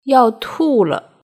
「あーもう飲めない！」「一緒に乾杯しましょう！」「もう一杯いかがですか？」など、お酒の席で知っておきたい、お酒を進めたり断ったり、お酒を通じて仲を深めたいと考えている時の中国語表現を（すぐに活用できるように？！）ネイティブの音声付きで紹介します。